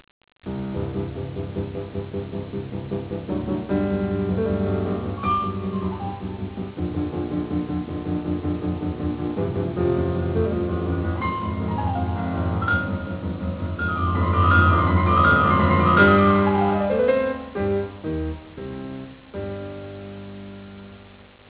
低音の反復音で始まり、突然最高音域に跳躍して旋律が奏される。
特に豊かに鳴る低音域の和音で力強さを出し、 同時に高音域のパッセージを奏でることなどにより、一層のダイナミックな表現を演出している。